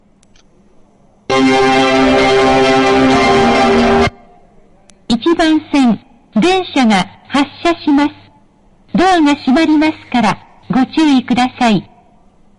発車メロディー
接近放送 「Verde Rayo V2」です。
設定を間違えたので、音量注意です･･･
●スピーカー：National天井丸型
●音質：良